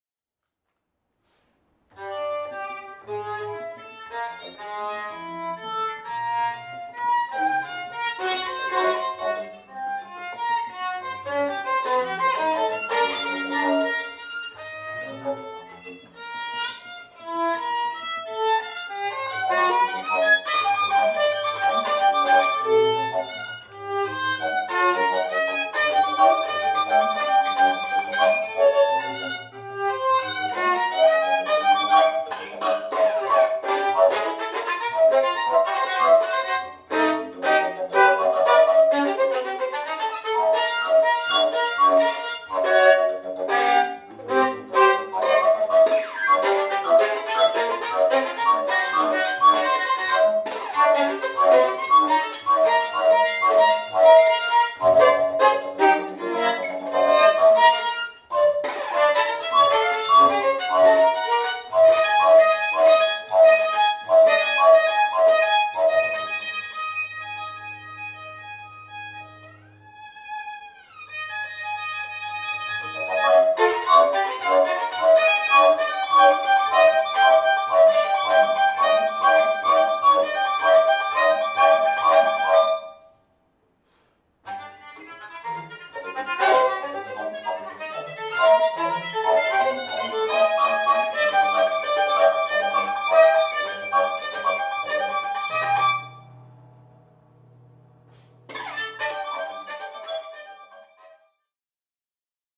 Live recording
2018 (Alte Schmiede, Vienna)
violin and paetzold contrabass recorder duration: 8’30”